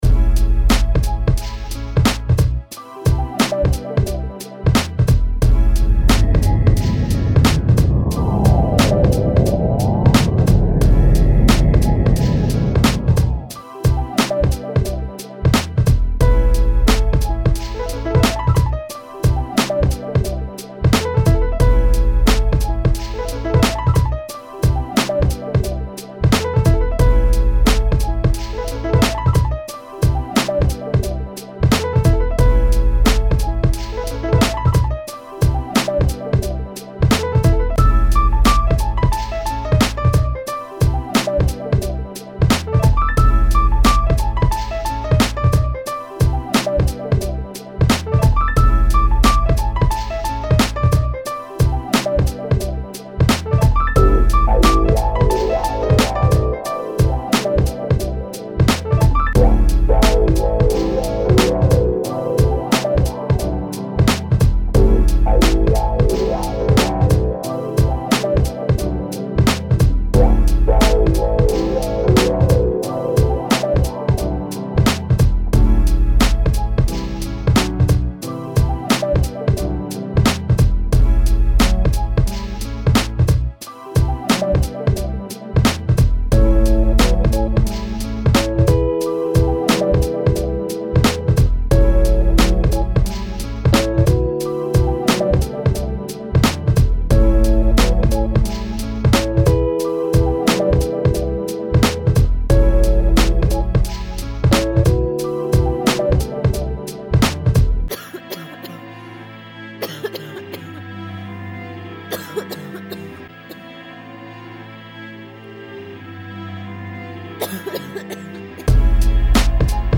Creator's Tags: abstract
Description: some home baked music! made with garageband and some live sounds.lo-fi